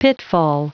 Prononciation du mot pitfall en anglais (fichier audio)
Prononciation du mot : pitfall